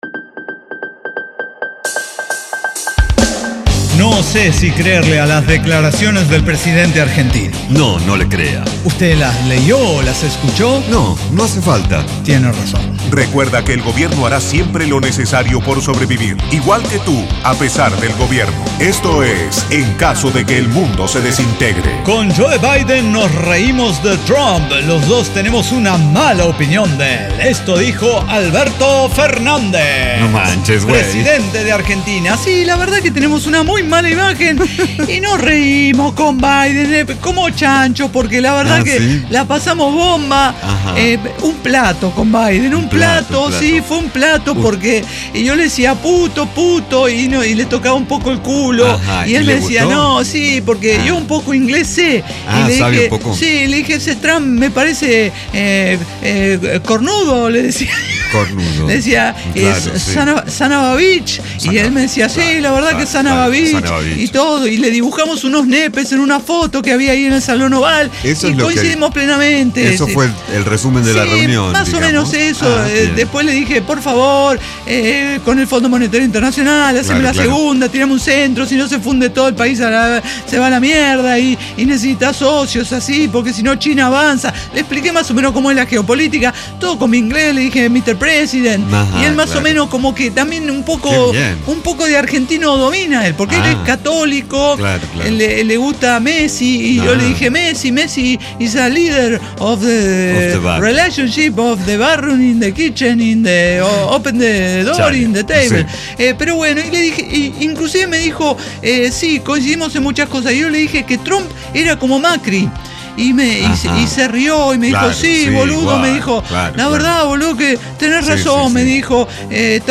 La experiencia de poseer limitaciones en los sentidos. El testimonio de un ciego que nos hace ver como es la vida de alguien con déficit visual.